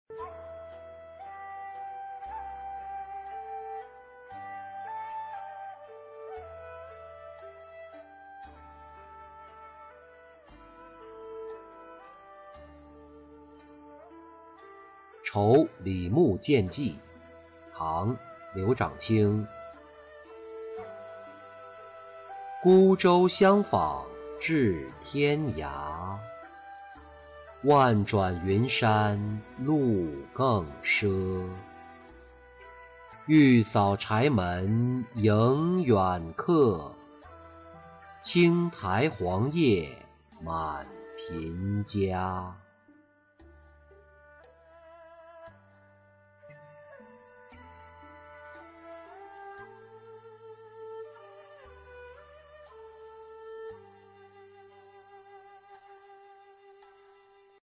酬李穆见寄-音频朗读